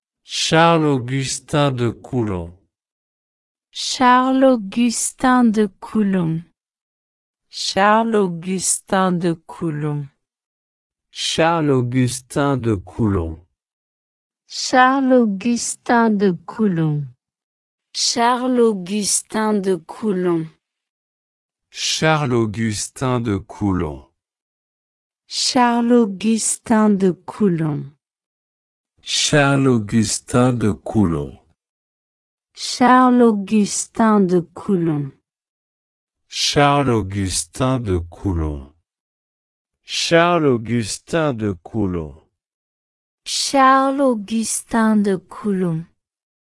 Die SI-Einheit der elektrischen Ladung ist das Coulomb, benannt nach Charles Augustin de Coulomb (gesprochen „Kulom“
Pronunciation_Charles_Augustin_de_Coulomb.ogg